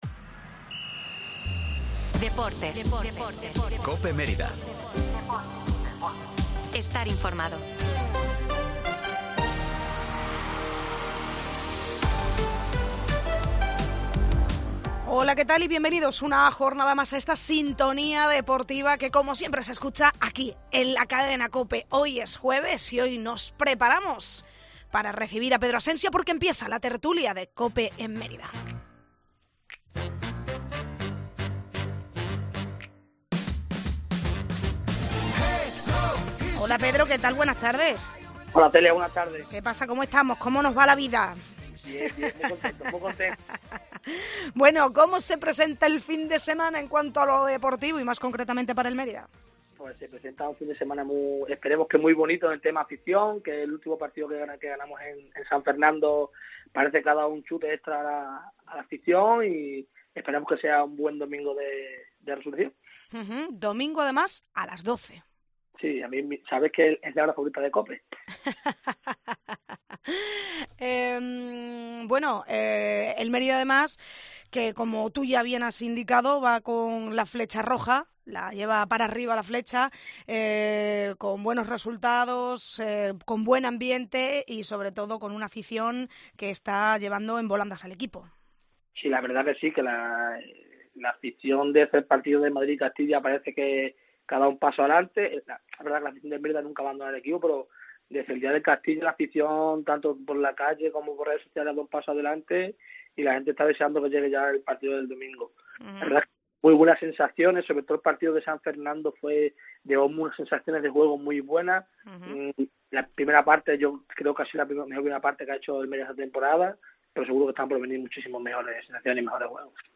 Tertulia del Mérida en COPE